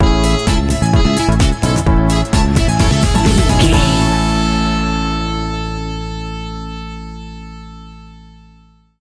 Ionian/Major
groovy
uplifting
bouncy
cheerful/happy
electric guitar
bass guitar
drums
synthesiser
saxophone
disco
upbeat